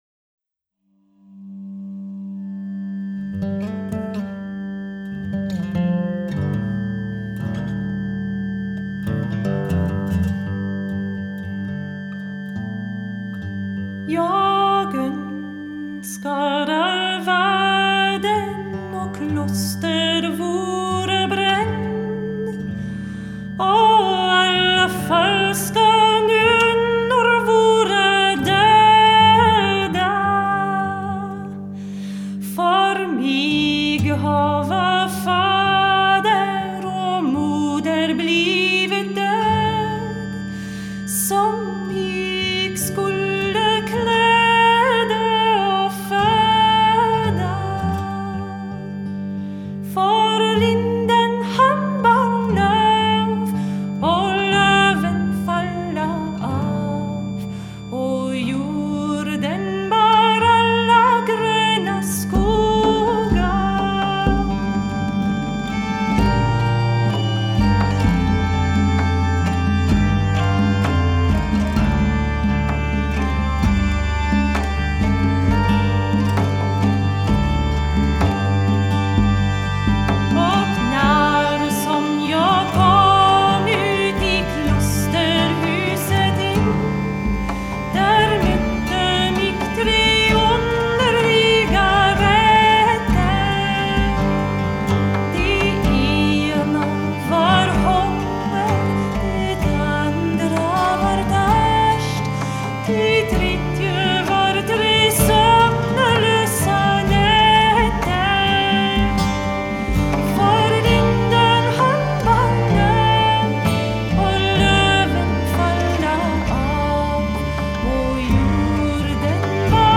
guitar
voice and nyckelharpa), is their first studio outing
melancholy  Swedish
trad. Sweden